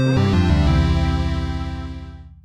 Techmino/media/effect/chiptune/achievement.ogg at 5f539a2d4f584e69fa5aed3c8b99287e2bc000db
achievement.ogg